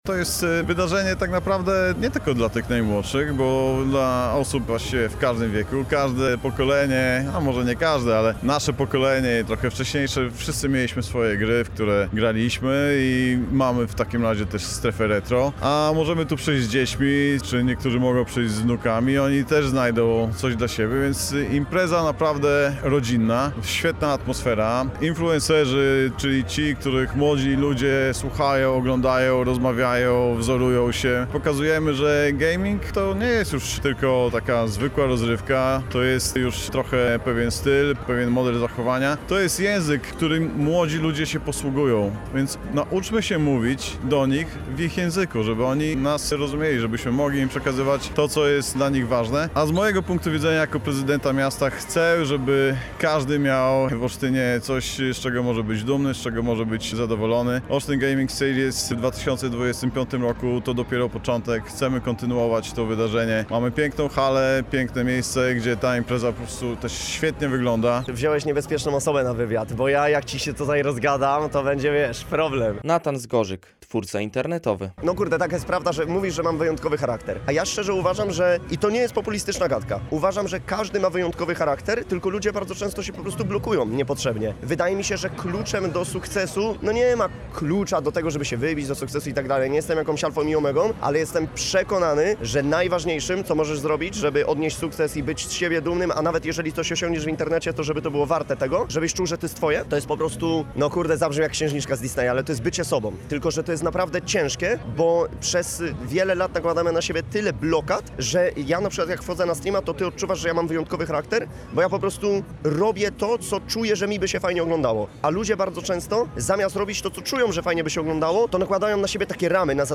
Jakie dokładnie strefy można było zobaczyć? Dlaczego gaming przyciąga tak wielu?